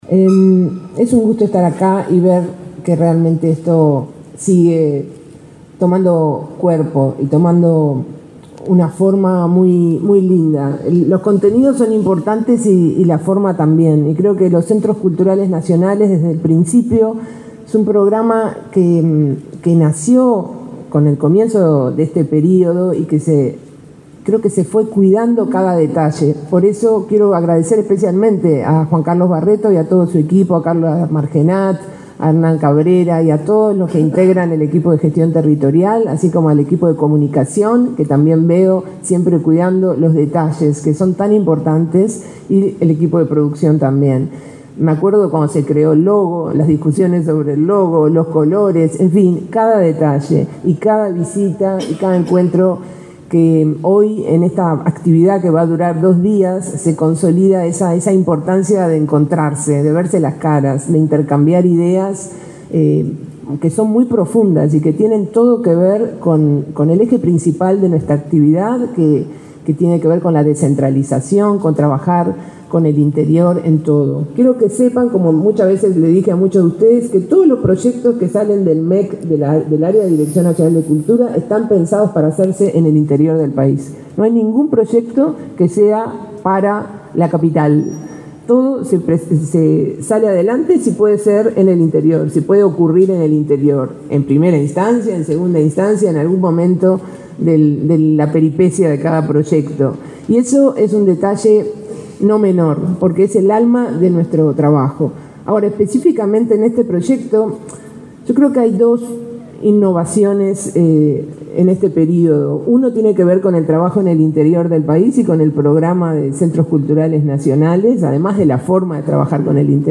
Palabras de la directora nacional de Cultura, Mariana Wanstein
Palabras de la directora nacional de Cultura, Mariana Wanstein 04/04/2024 Compartir Facebook Twitter Copiar enlace WhatsApp LinkedIn La directora nacional de Cultura del Ministerio de Educación y Cultura, Mariana Wanstein, participó, este jueves 4 en Montevideo, en la apertura del Primer Encuentro de Centros Culturales Nacionales.